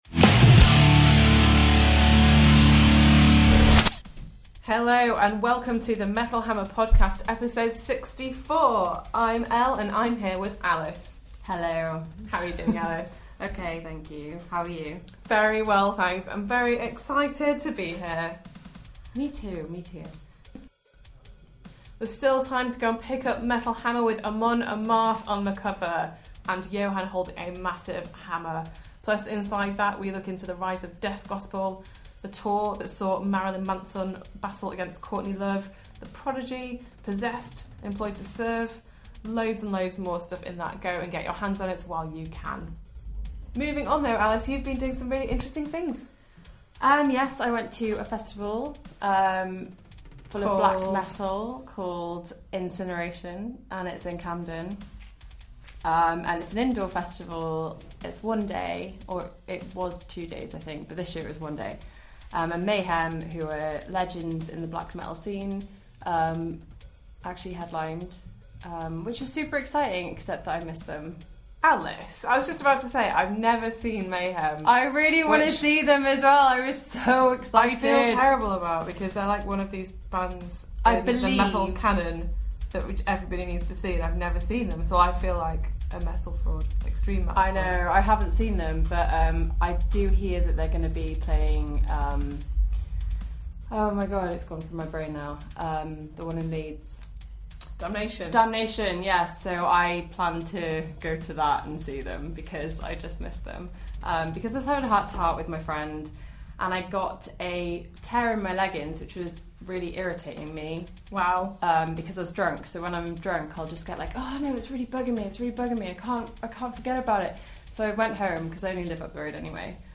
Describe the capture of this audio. in the studio this week